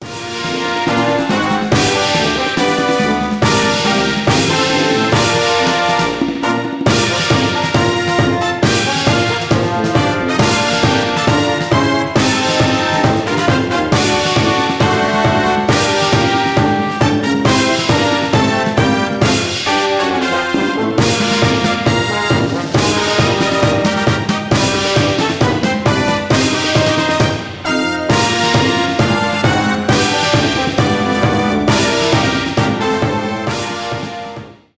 инструментальные , марш , без слов
оркестр